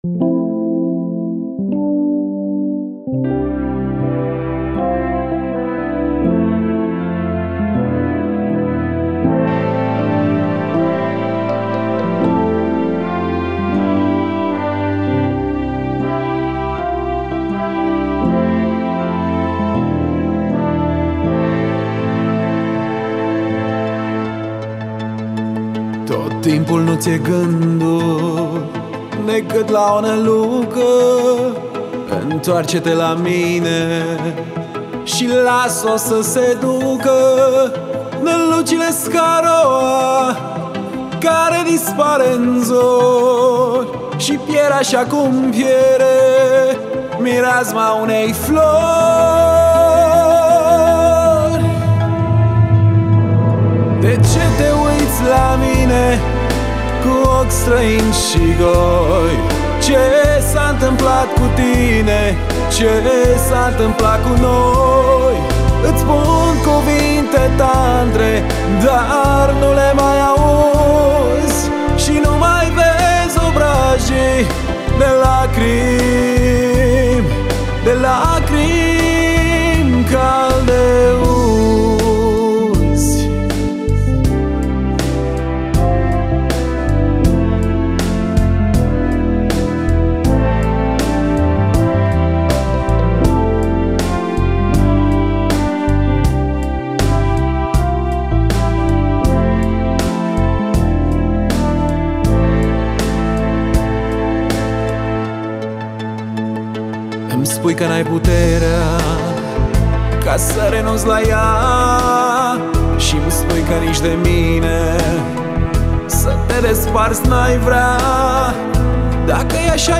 voce